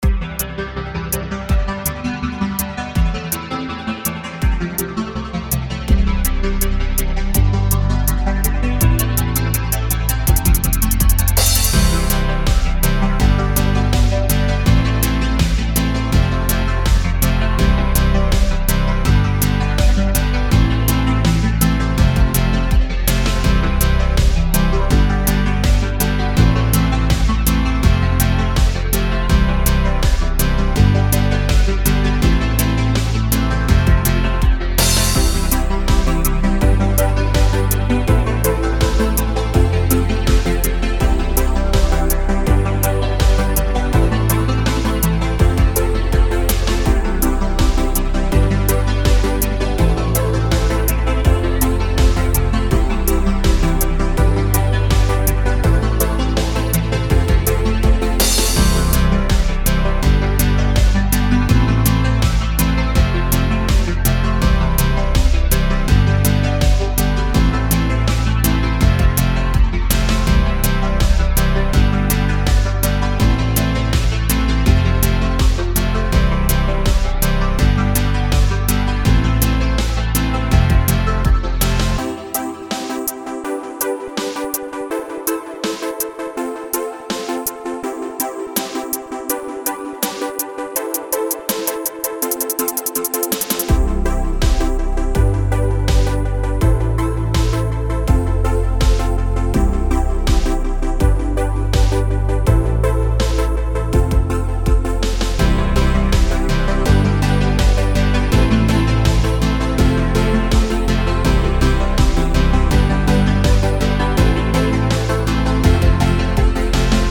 FL Studio with some basic chord progression.
Synthwave